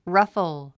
発音
rʌ́fl　ラッフォゥ